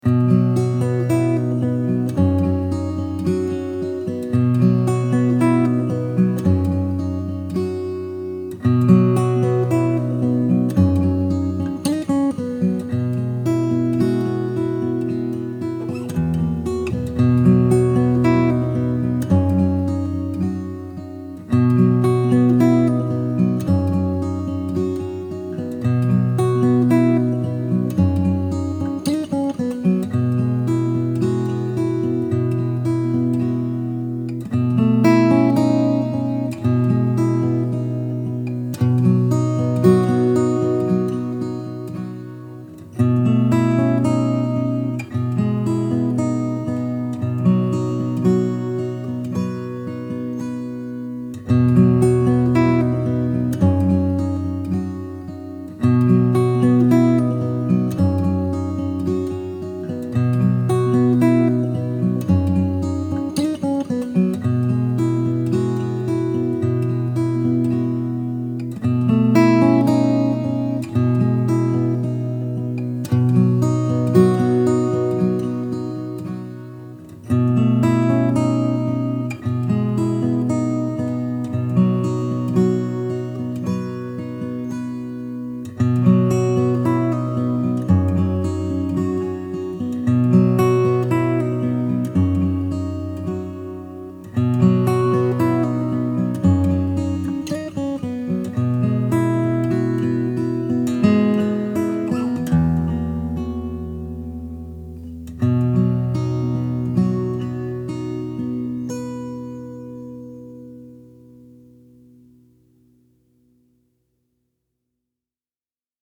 آرامش بخش , عصر جدید , گیتار , موسیقی بی کلام
گیتار آرامبخش